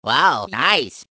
One of Luigi's voice clips in Mario Kart Wii